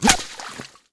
auto_hit_liquid2.wav